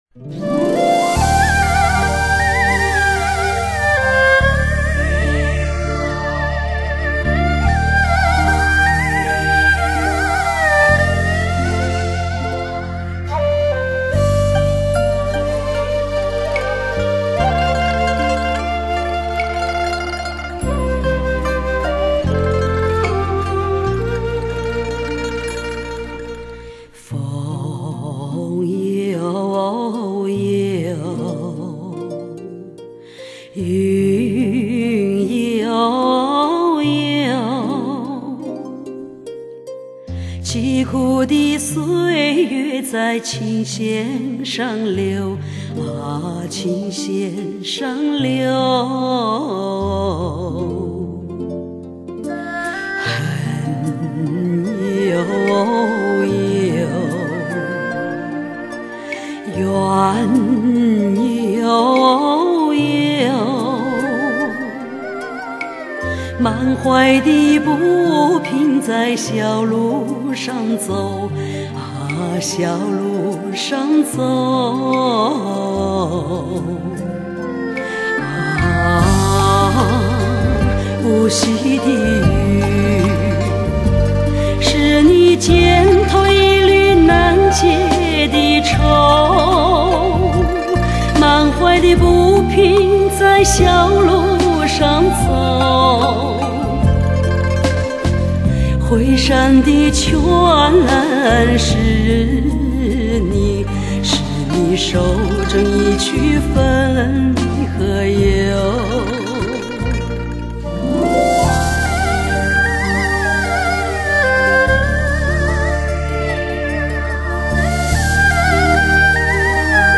雕刻时光的女声，忘情沉醉的天响
童声簇拥中吐露风雨情怀